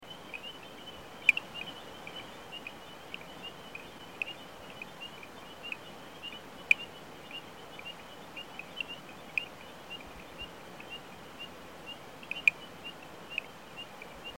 Play Especie: Pristimantis platydactylus Género: Pristimantis Familia: Strabomantidae Órden: Anura Clase: Amphibia Título: Guía sonora de las ranas y sapos de Bolivia.
Localidad: Bolivia: Carretera vieja Paractito-Cochabamba, Chapare, Cochabamba
44 Eleutherodactylus Platydactylus.mp3